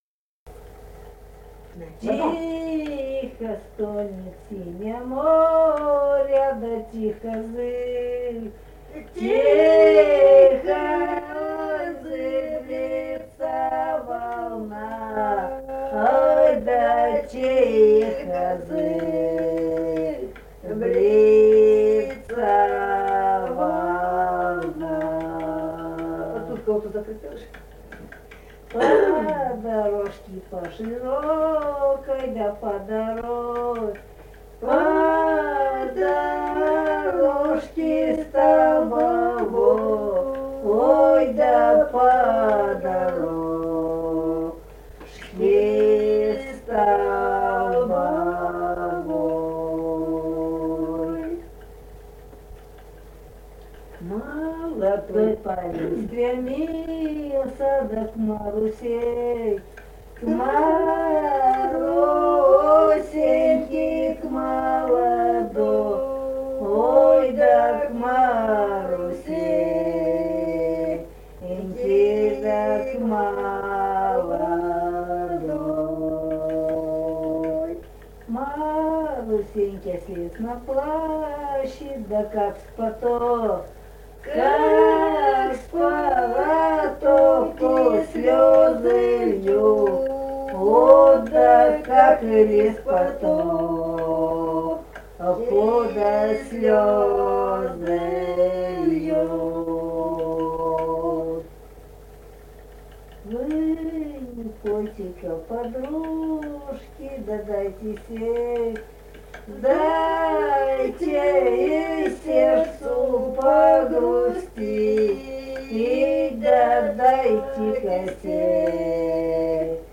Русские песни Алтайского Беловодья 2 «Тихо стонет сине море», лирическая.
Республика Казахстан, Восточно-Казахстанская обл., Катон-Карагайский р-н, с. Белое.
Прим: пели в компании.